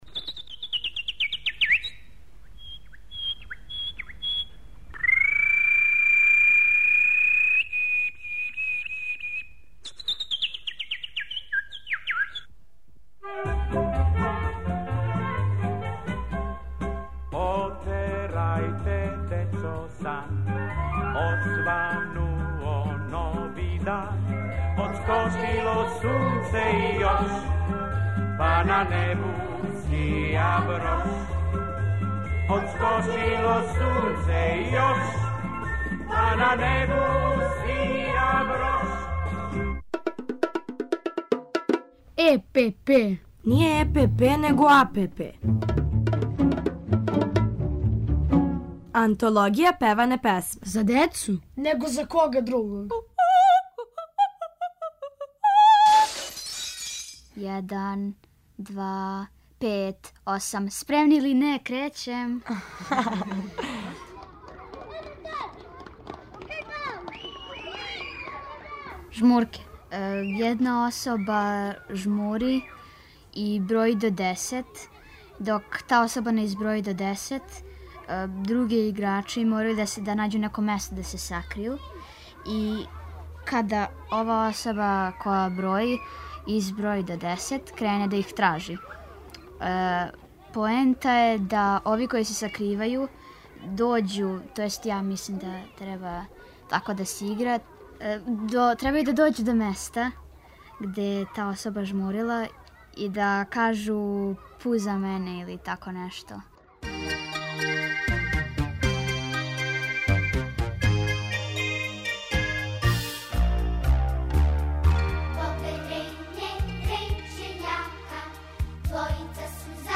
У серијалу АНТОЛОГИЈА ПЕВАНЕ ПЕСМЕ певамо и размишљамо о игрању и играма...